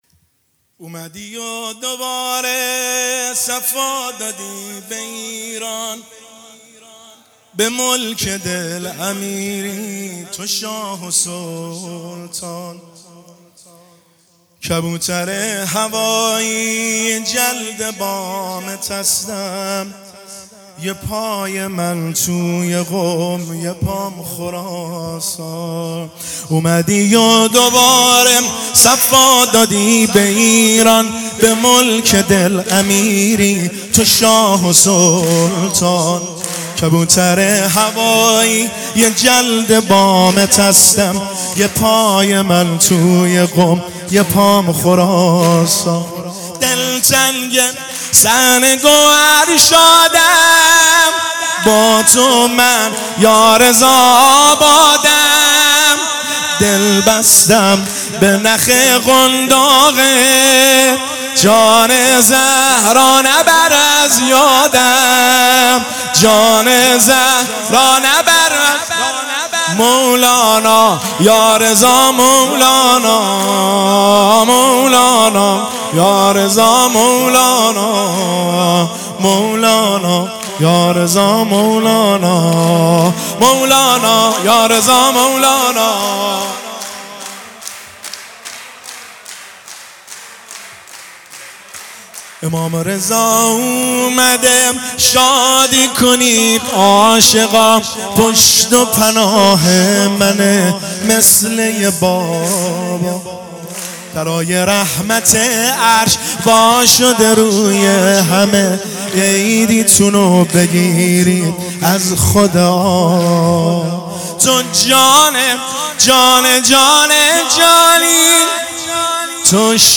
میلاد حضرت علی بن موسی الرضا (ع)